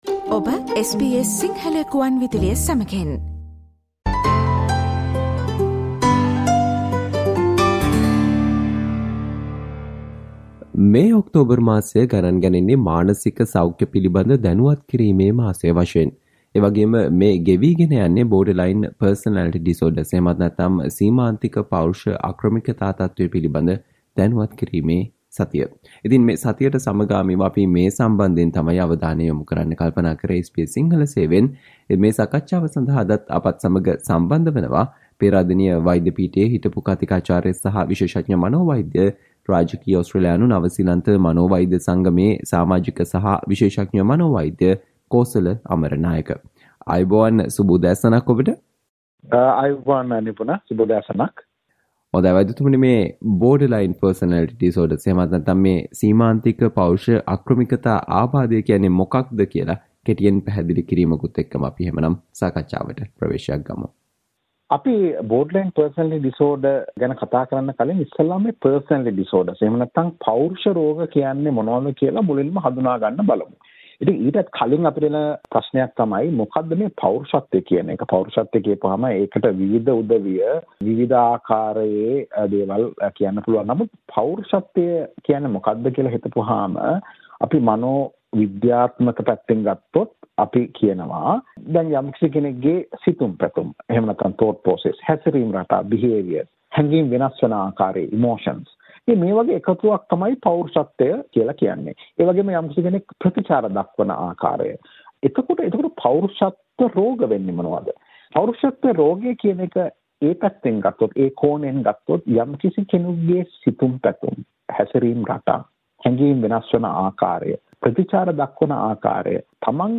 SBS Sinhala discussion on What you need to know about Borderline Personality Disorder, which affects one in four Australians